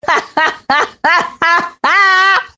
Darmowe dzwonki - kategoria Śmieszne
Dzwonek - Kpiący śmiech sąsiadki
Ironiczny śmiech kobiety. Być może nie jeden powiedziałby, że słyszy śmiech drwiącej sąsiadki :)
kpiacy-smiech-sasiadki.mp3